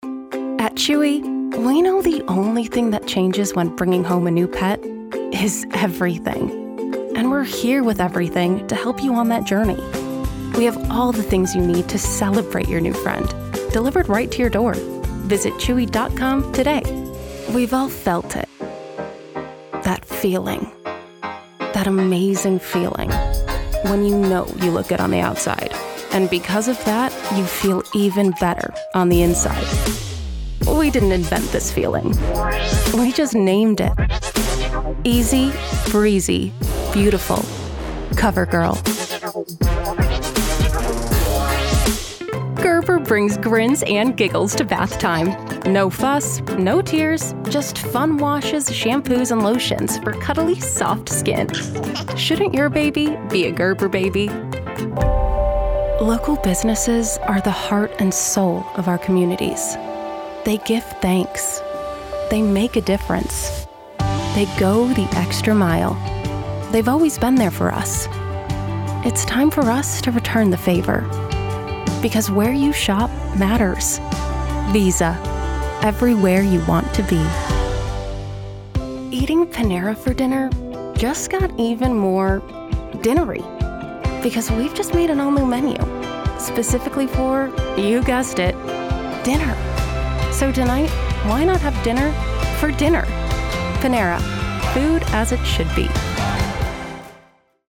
Her American voice showcases a conversational, matter of fact tone. Her voice is friendly, reassuring and youthful.
Female / 20s, 30s / American, English / American
Showreel